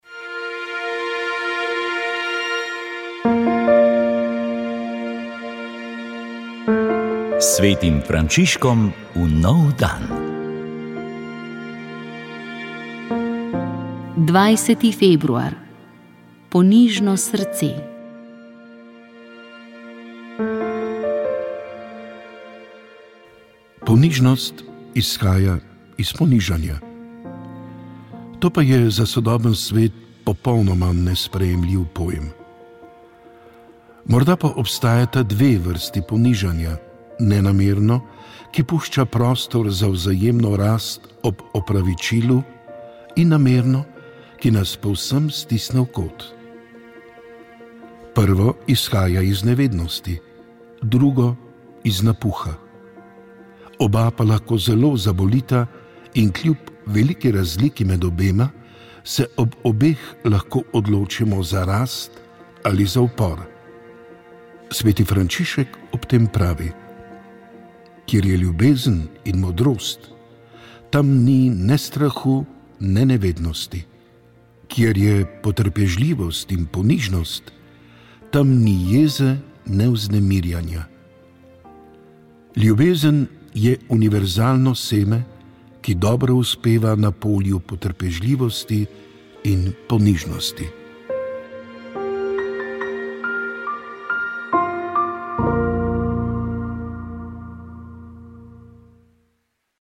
Društvo Združeni ob lipi sprave, Nova slovenska zaveza in pobuda Vseposvojitev so v ponedeljek, 15. junija ljubljanskih Žalah pripravili spominsko slovesnost. Tokrat je bila v znamenju 30. obletnice prvih svobodnih volitev.